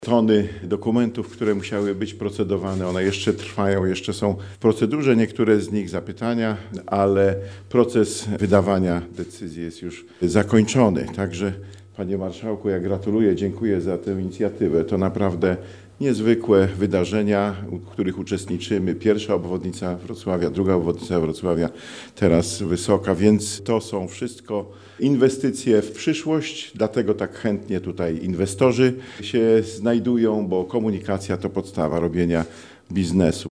-To wielki dzień dla Wrocławia i mieszkańców, dodaje Bogusław Szpytma, Wicewojewoda Dolnośląski.